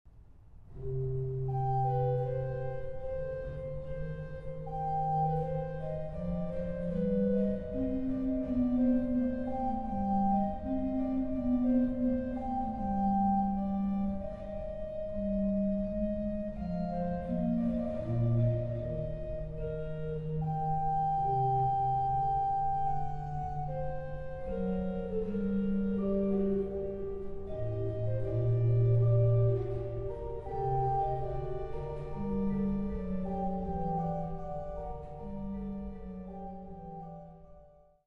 Knipscheer-orgel